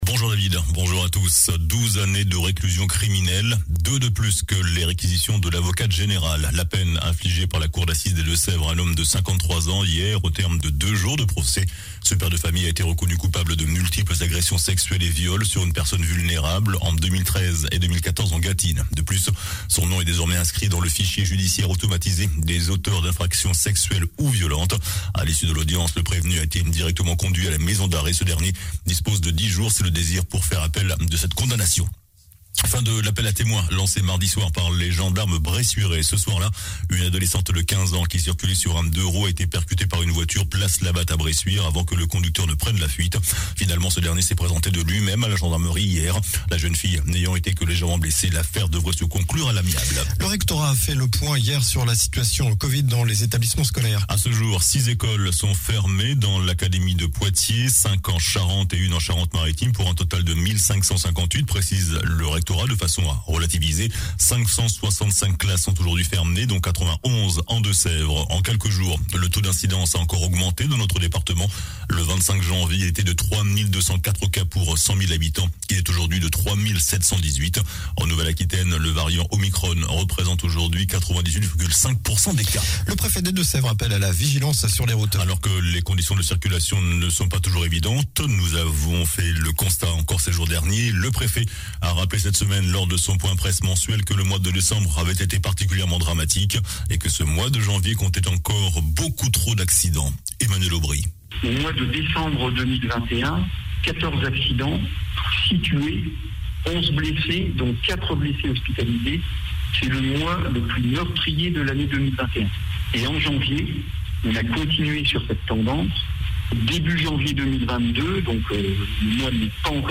JOURNAL DU SAMEDI 29 JANVIER